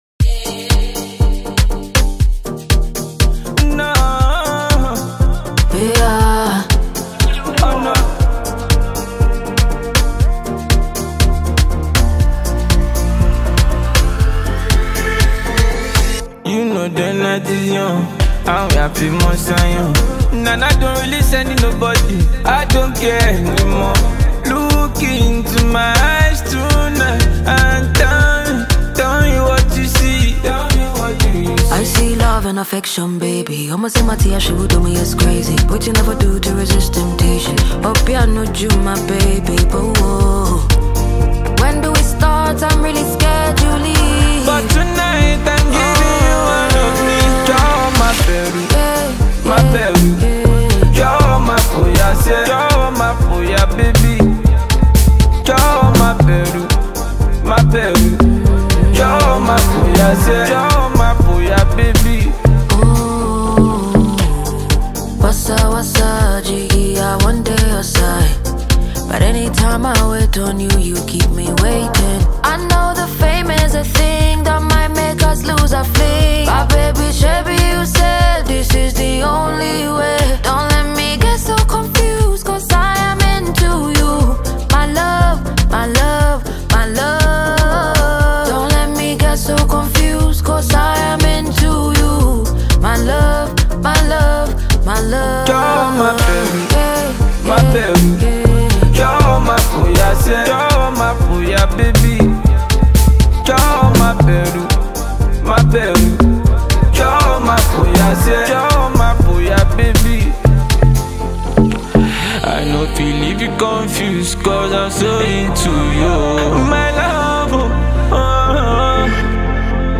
Talented Ghanaian female singer and songwriter